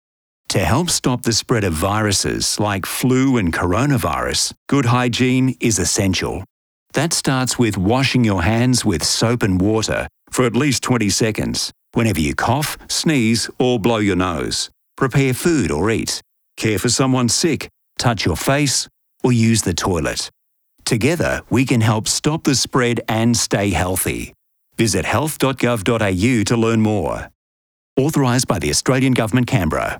coronavirus-covid-19-radio-simple-steps-to-stop-the-spread.wav